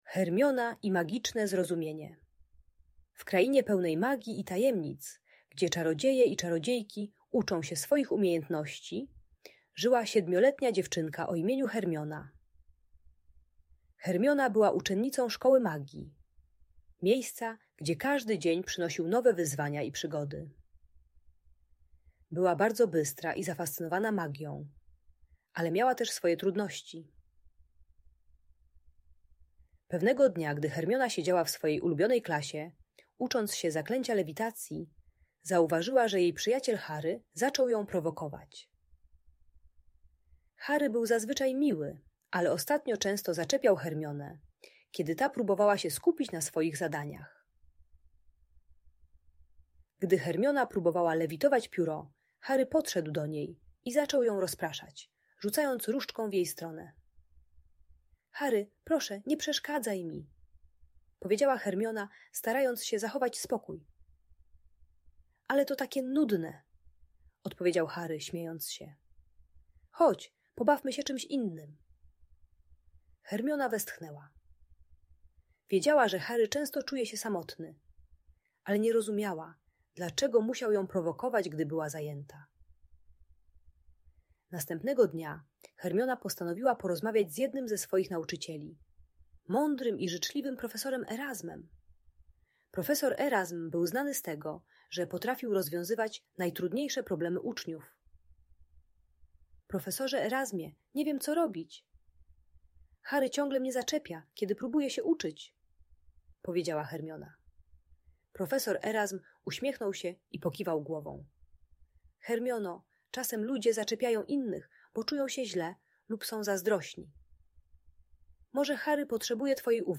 Historia Hermiony i Magicznego Zrozumienia w Szkole Magii - Audiobajka